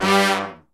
G2 POP FALL.wav